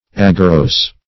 Aggerose \Ag`ger*ose"\, a. In heaps; full of heaps.
aggerose.mp3